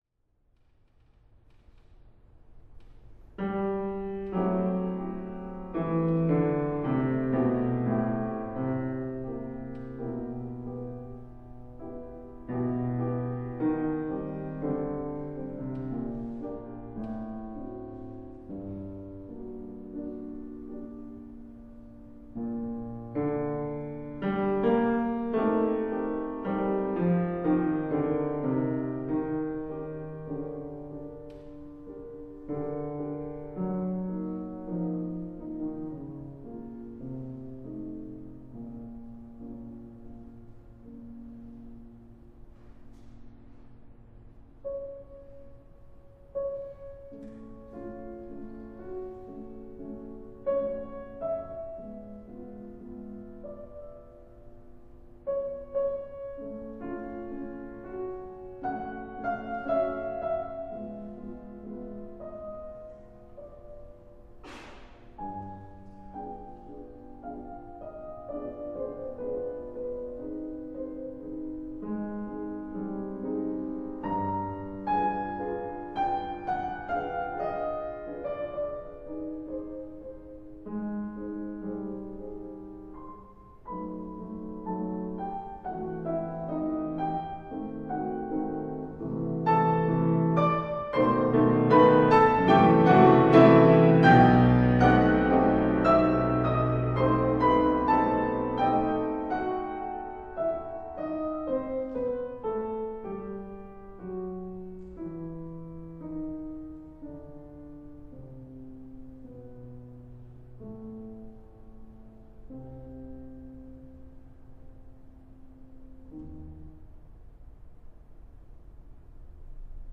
Went a little easy on the amplification - in the original, there was far too much buzzing.